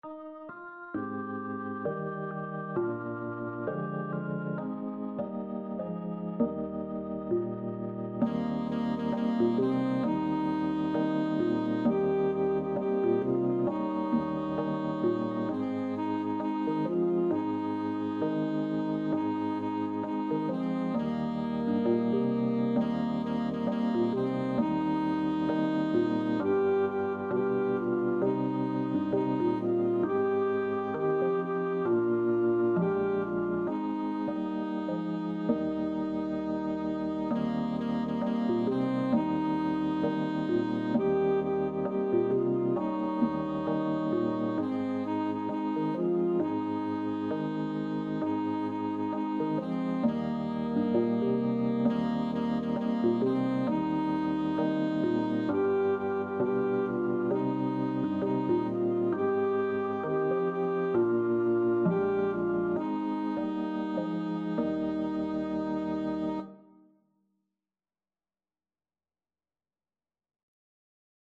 Pop (View more Pop Alto-Tenor-Sax Duet Music)